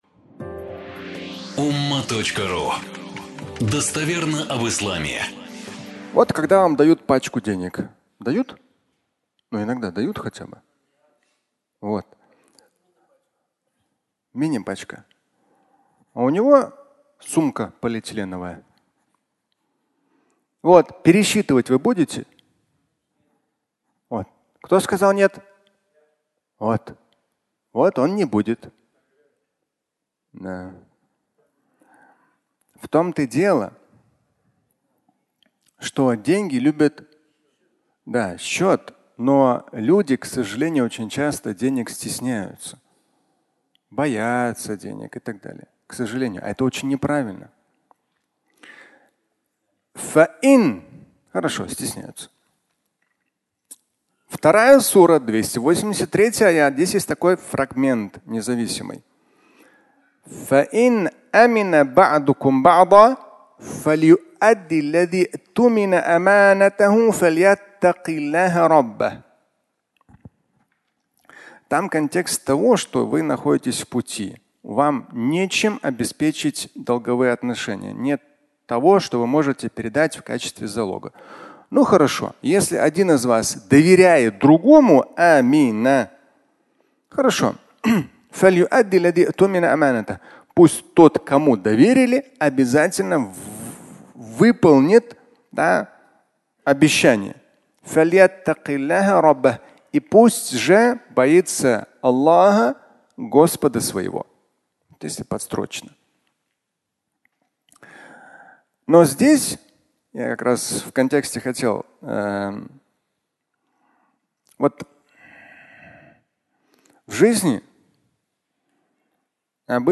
«Я ему доверяю» (аудиолекция)